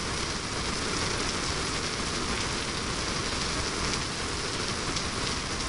st_rainloop.wav